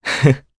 Crow-Vox_Happy1_jp.wav